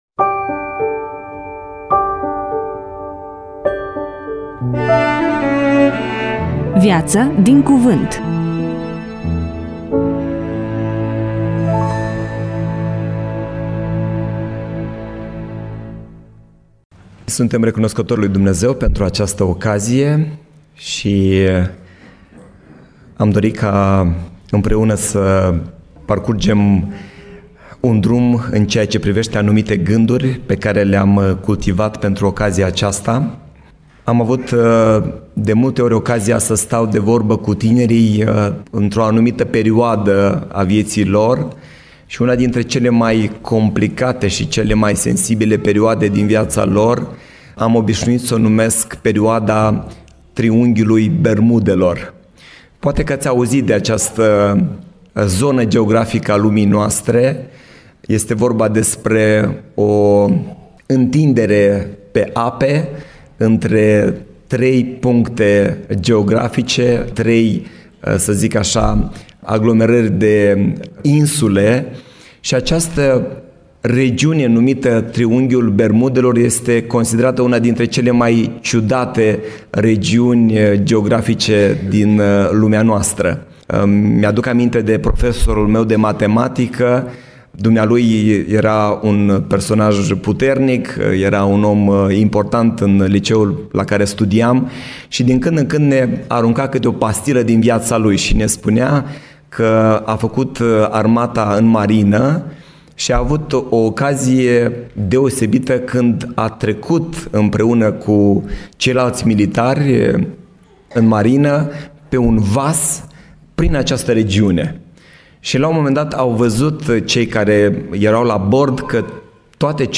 EMISIUNEA: Predică DATA INREGISTRARII: 28.03.2026 VIZUALIZARI: 20